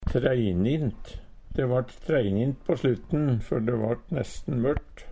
treinint - Numedalsmål (en-US)